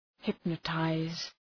Προφορά
{‘hıpnə,taız}